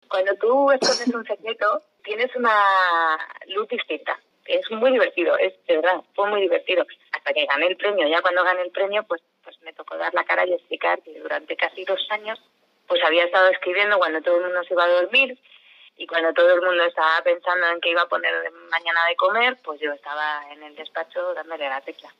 con sinceridad formato MP3 audio(0,41 MB).